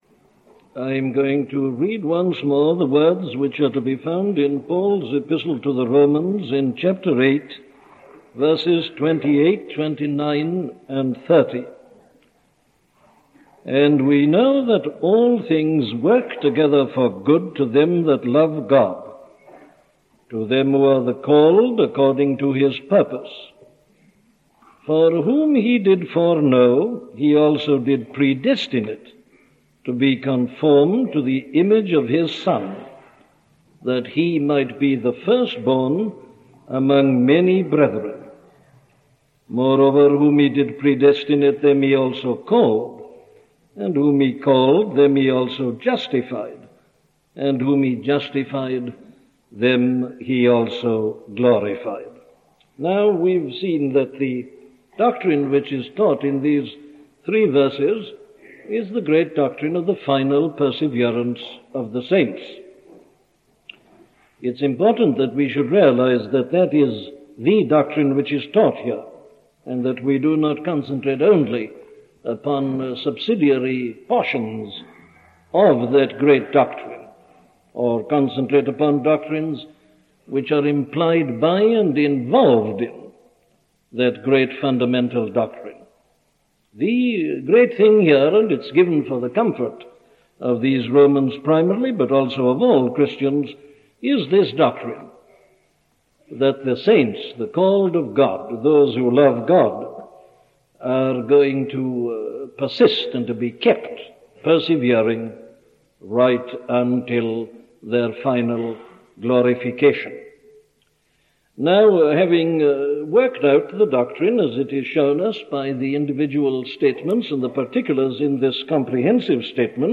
The Unbreakable Chain - a sermon from Dr. Martyn Lloyd Jones